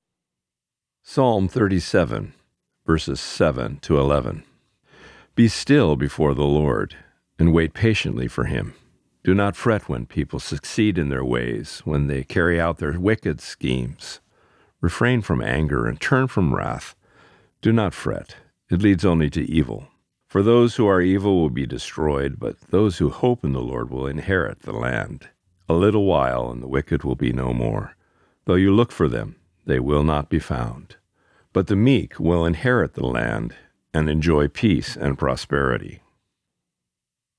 Reading: Psalm 37:7-11